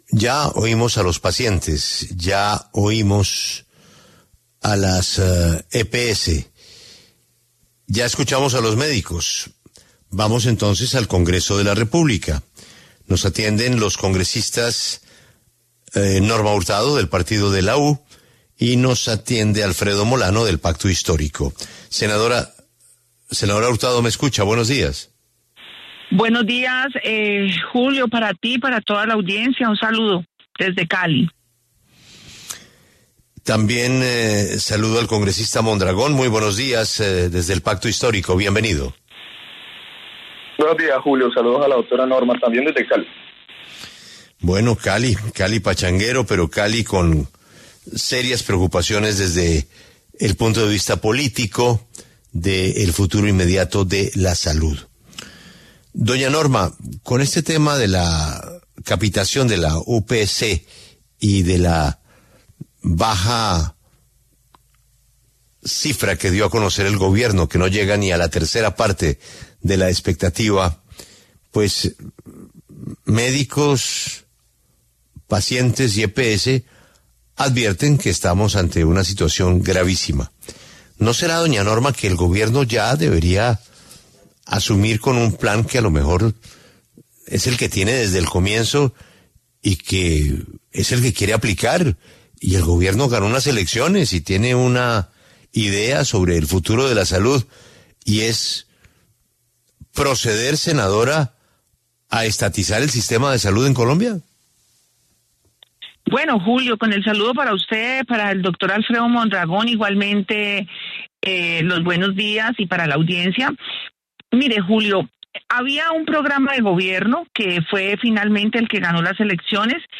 Norma Hurtado y Alfredo Mondragón debatieron en La W sobre el aumento de la UPC para el 2025.